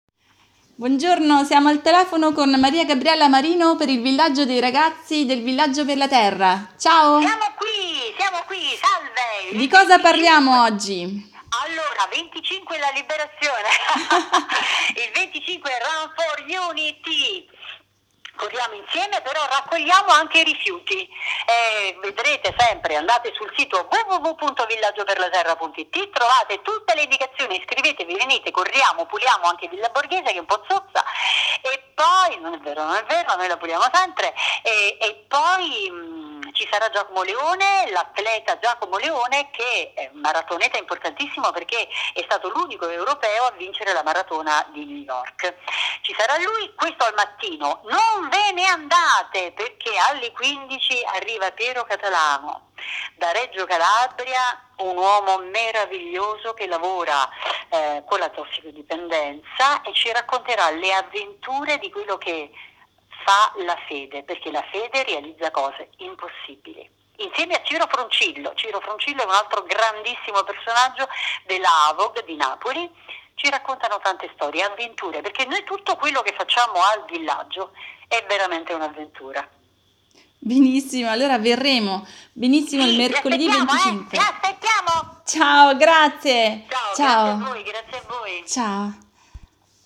Cosa succede Mercoledi 25 Aprile, ne parliamo al telefono